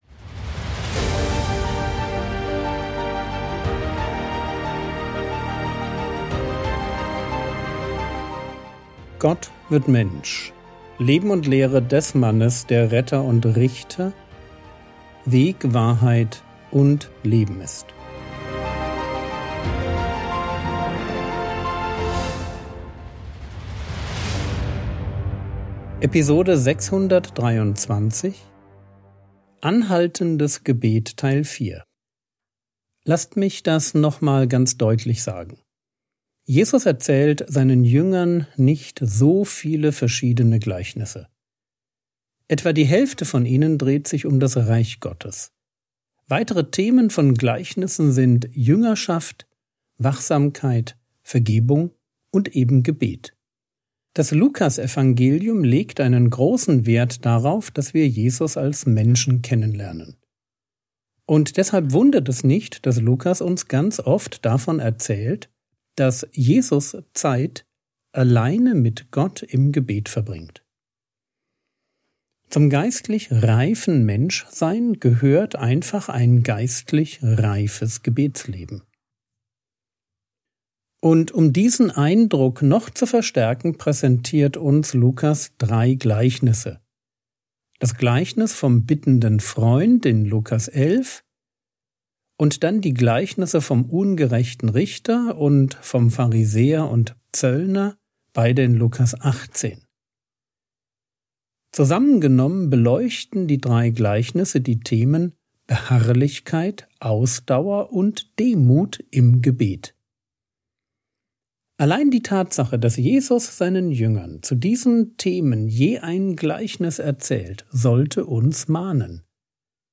Episode 623 | Jesu Leben und Lehre ~ Frogwords Mini-Predigt Podcast